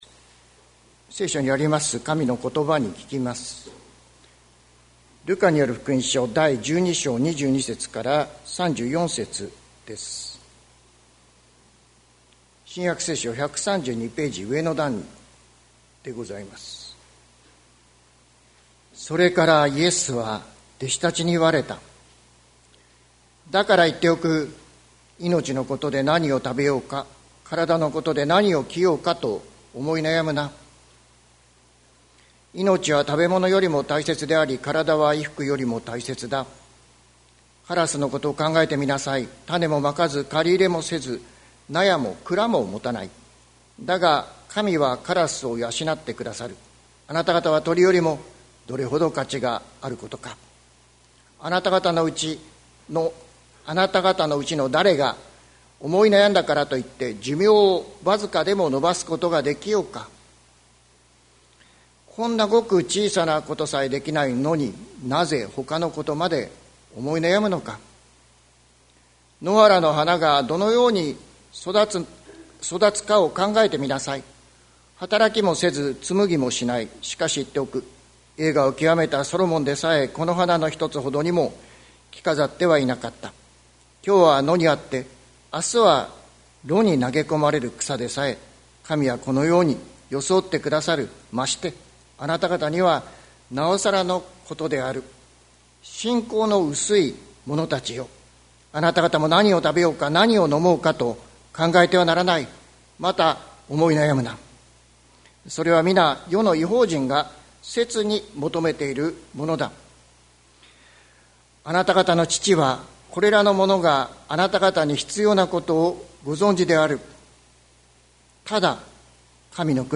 2025年01月26日朝の礼拝「恐れるな、小さい群れよ」関キリスト教会
説教アーカイブ。